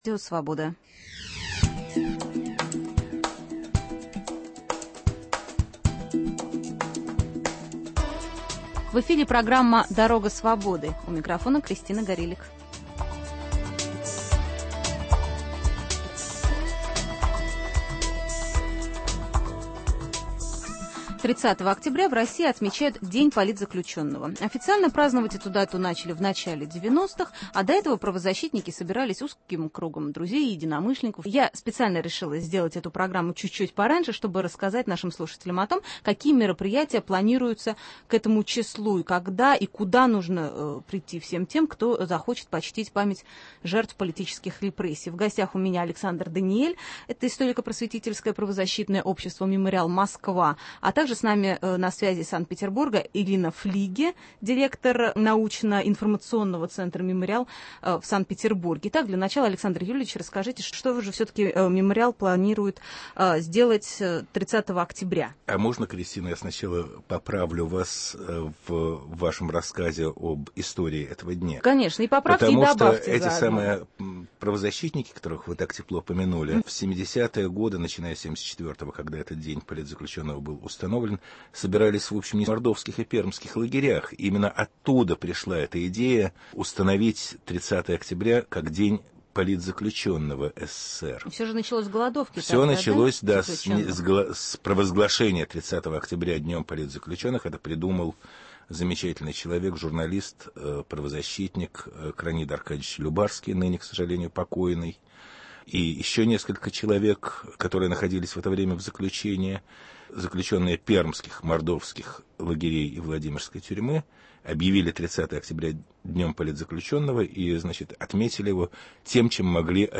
Также в программе: интервью с людьми из разных российских регионов, которые в одиночку сохраняют для будущих поколений память о советском терроре. Людмила Алексеева рассказывает про закон о реабилитации жертв политических репрессий.